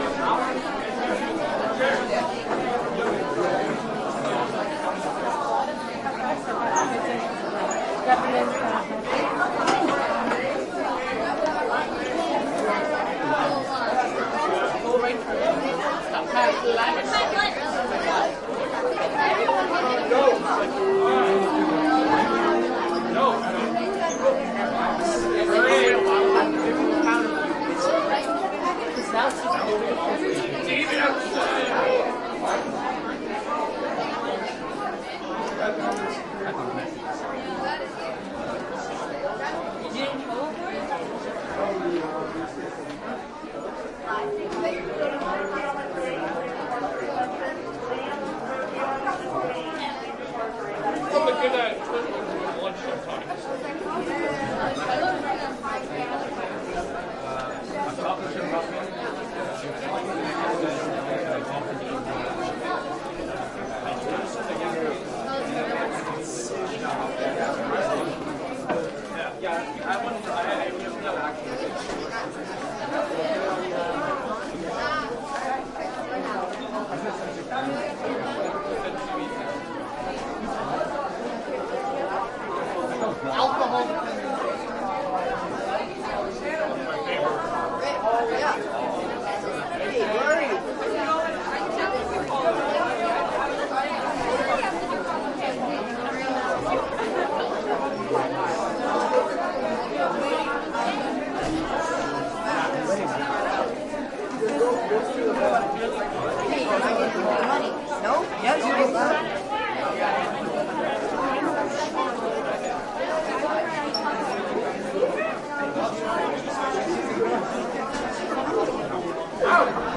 高中" 人群中 高中大走廊 中等繁忙的课间时间 学生浪潮到食堂 + 对讲机
描述：人群int高中大走廊中等忙碌的学生到自助餐厅+对讲
Tag: INT 走廊 人群 中等 学校